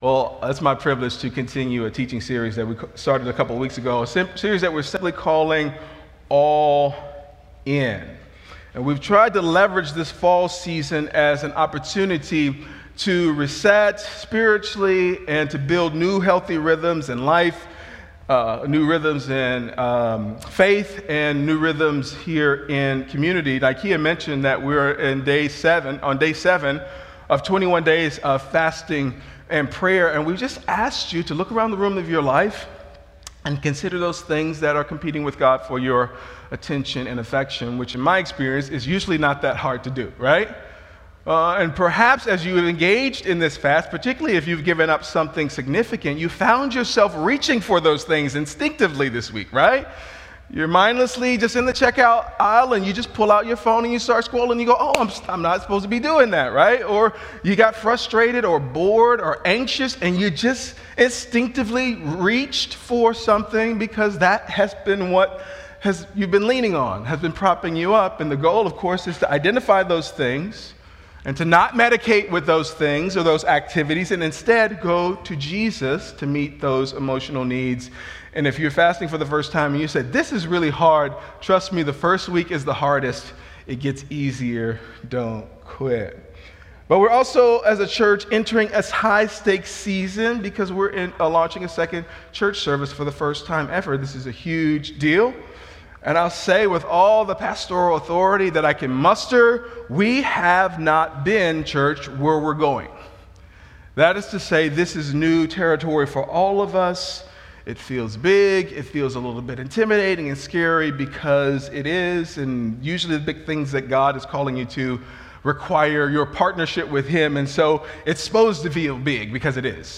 Weekly preaching podcast from the South Suburban Vineyard Church in Flossmoor, IL.